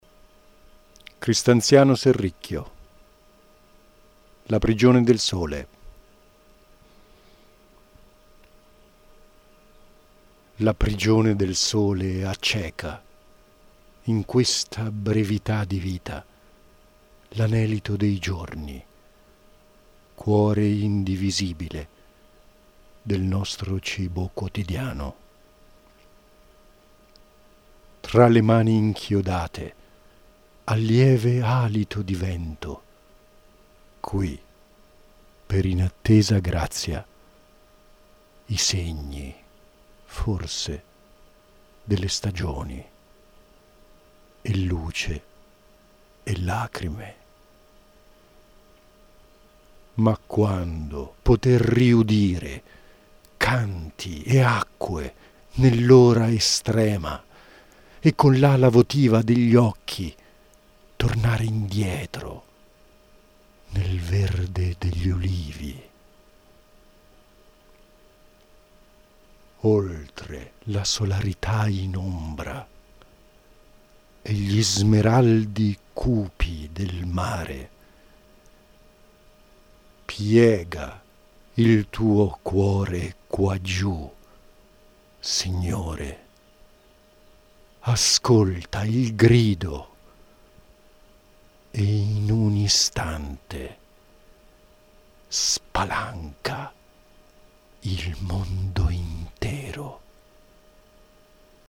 A partire dal 2006 ho partecipato ad alcuni incontri di lettura di poesie al Centro Coscienza di Milano, in Corso di Porta Nuova, 16.